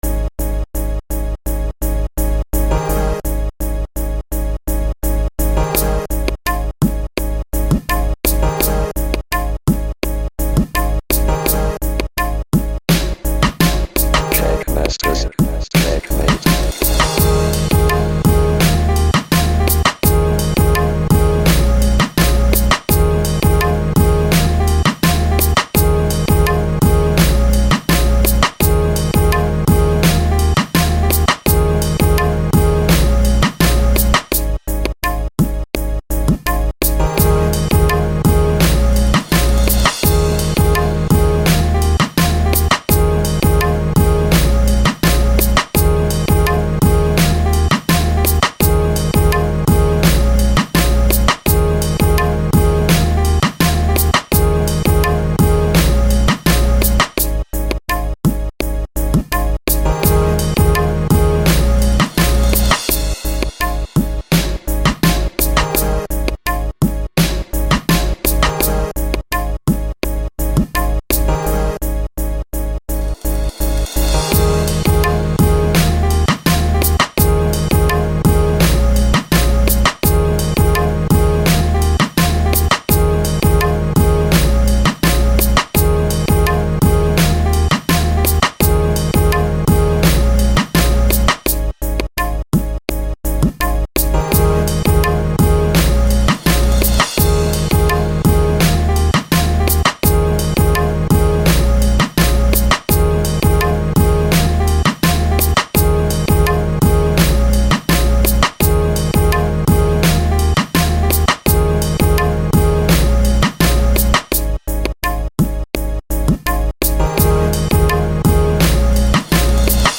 제가 무슨 의도로 어떻게 만든지도 잘 알수 없는 비트입니다.
Bpm도 랩하기 딱 좋게 92정도이고...
오, 사운드 괜찮다. 근데 좀 어지러움
특이하면서 뭔가 신선한것 같은데 좀 난해하다는 느낌입니다.
보통 스네어 2박 4박에 들어가는데 3박 4박에 넣으셨네요.